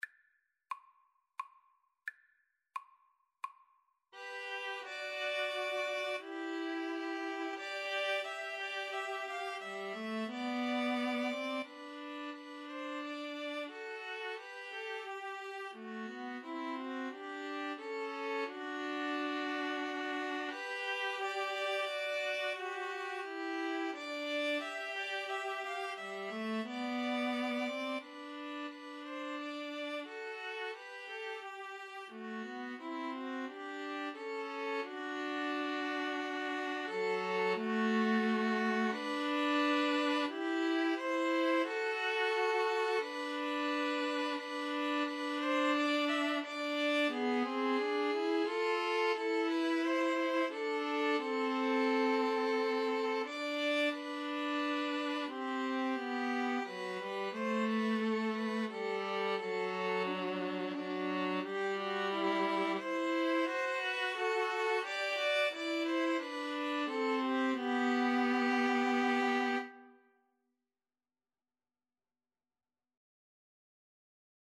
G major (Sounding Pitch) (View more G major Music for String trio )
Andante Cantabile ( = c.88)
3/4 (View more 3/4 Music)